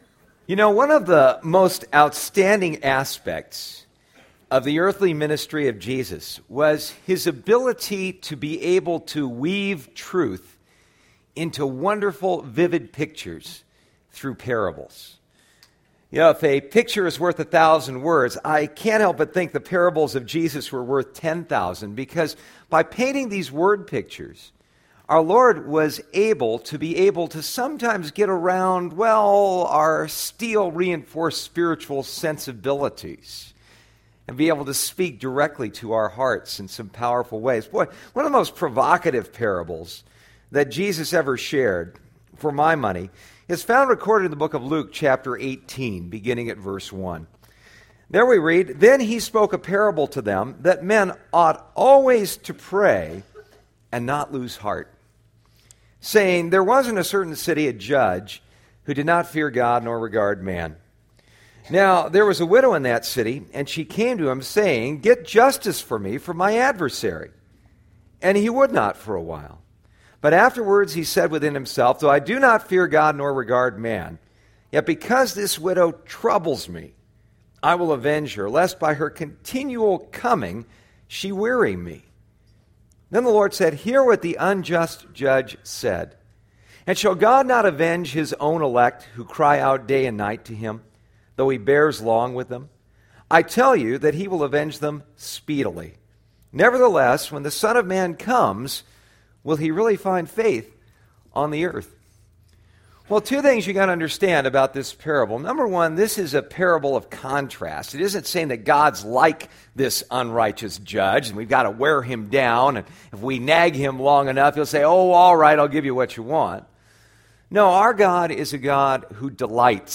Psalm 54 Service Type: Sunday Morning « Tackling Tough Transitions Psalm 53